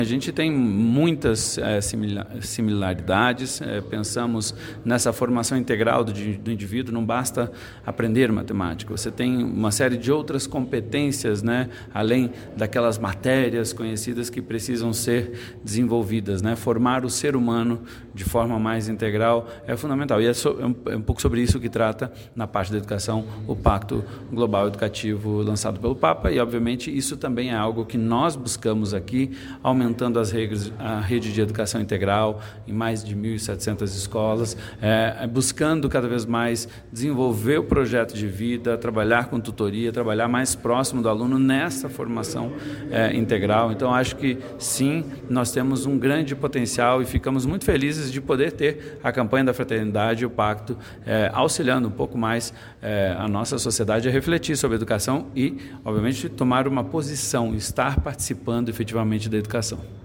Em entrevista ao portal diocesano o secretário falou sobre a importância da Campanha para a conjuntura de ensino do Brasil: